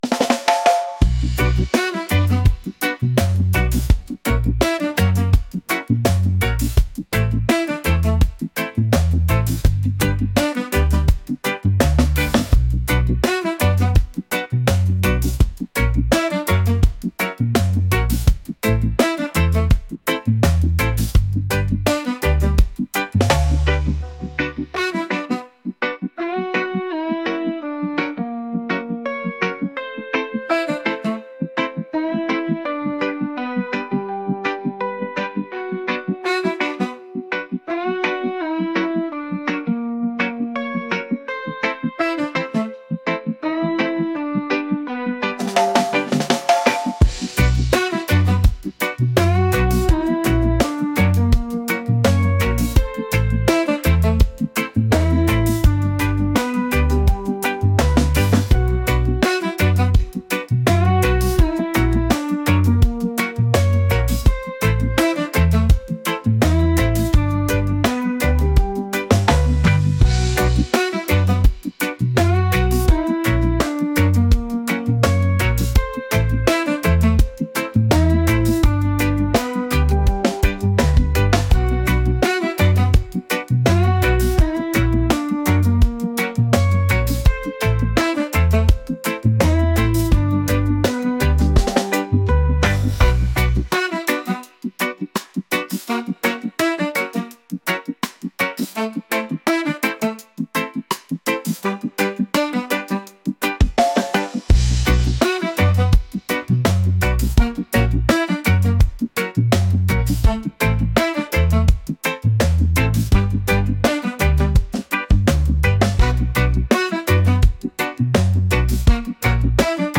reggae | upbeat | groovy